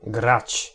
ɡ g
grać girl